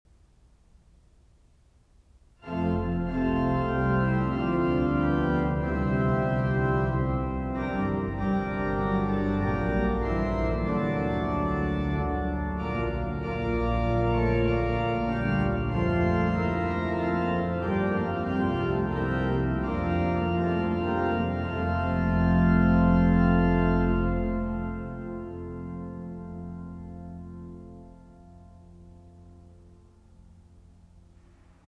Mottlied_-_Orgelintonation.mp3